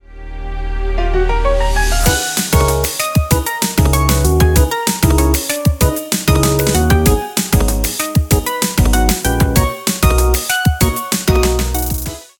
applause.ogg